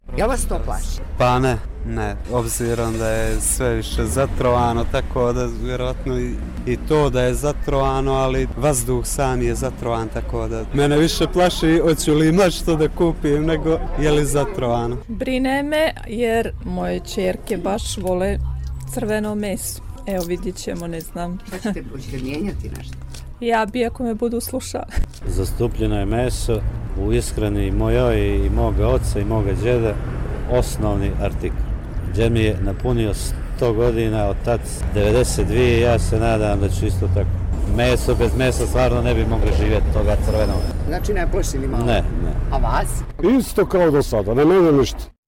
Anketa - Crna Gora